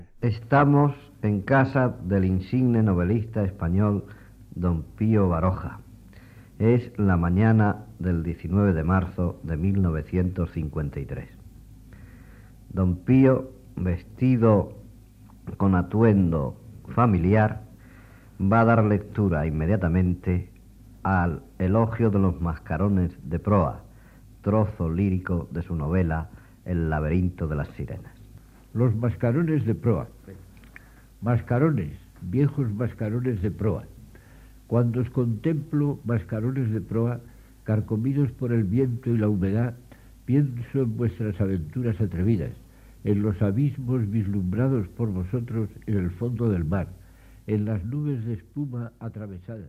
Data i presentació de l'escriptor Pío Baroja que, a casa seva, llegeix un fragment de la novel·la "El laberinto de las sirenas"